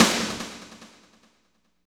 51.06 SNR.wav